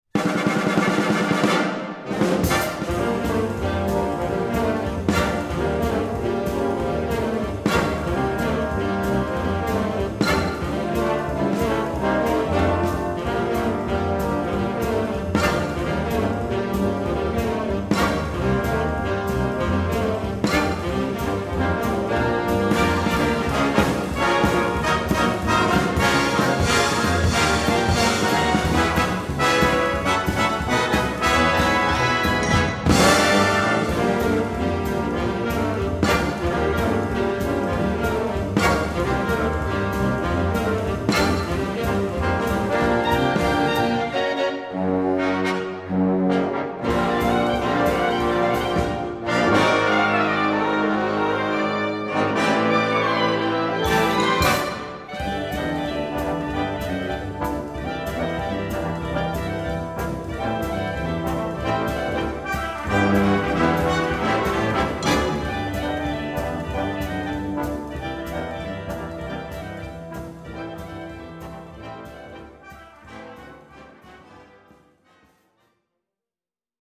10 Piece Brass Ensemble
Ensemblemusik für 10 Blechbläser